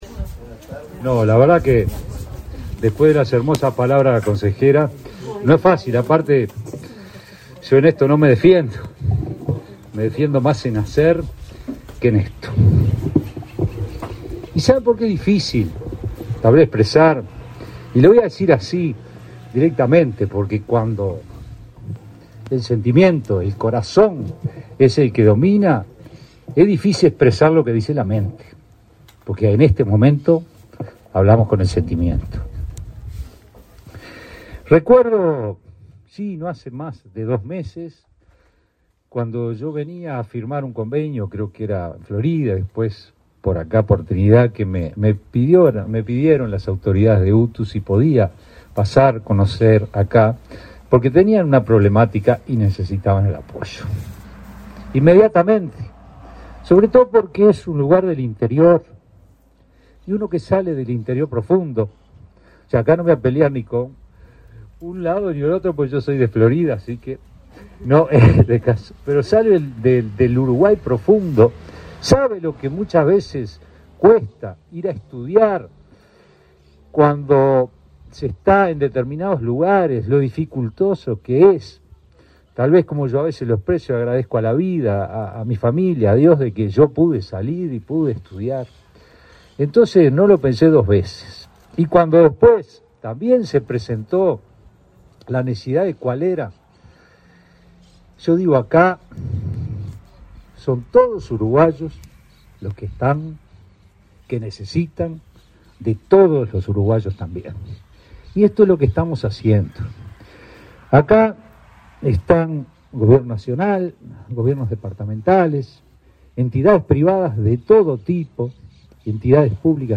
Palabras del director de UTU, Juan Pereyra
Palabras del director de UTU, Juan Pereyra 14/04/2023 Compartir Facebook X Copiar enlace WhatsApp LinkedIn La Dirección General de Educación Técnico Profesional-UTU presentó, este 14 de abril, un proyecto educativo para Flores. Participó en el acto el director general UTU, Juan Pereyra.